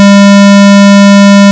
bruit.wav